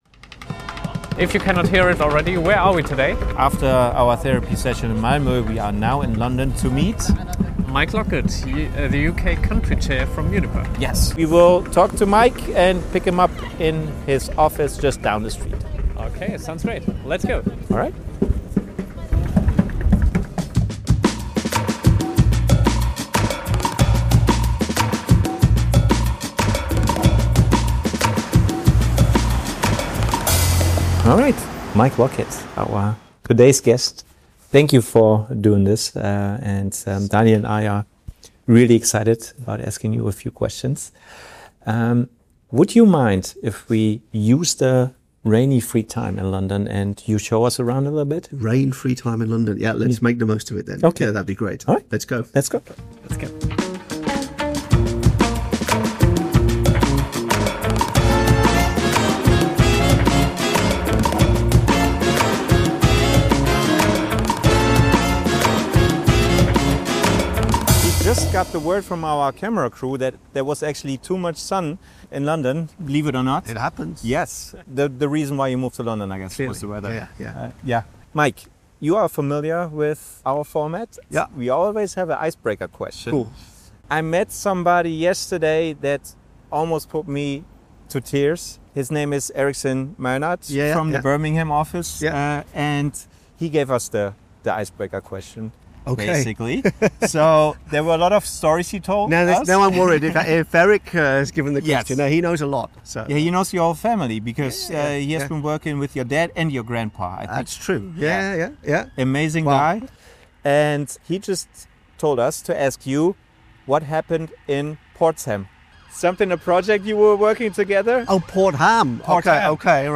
Begleiten Sie die drei auf einem Spaziergang entlang des Buckingham Palace